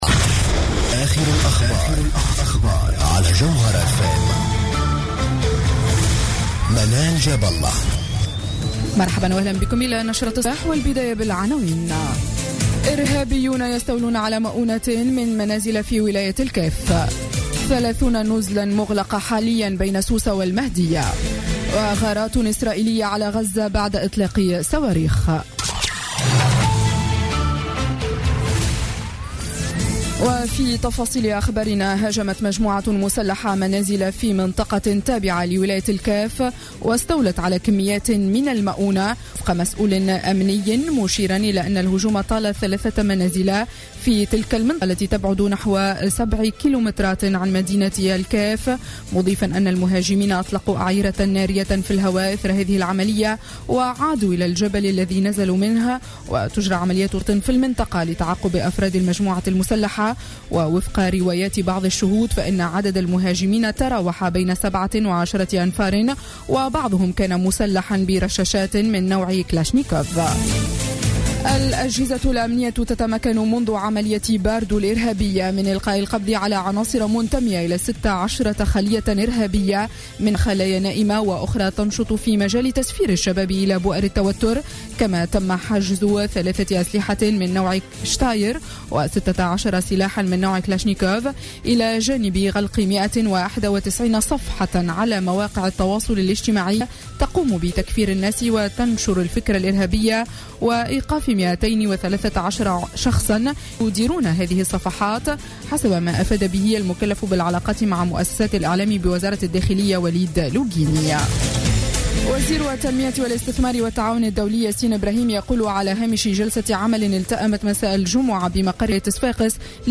نشرة أخبار السابعة صباحا ليوم السبت 19 سبتمبر 2015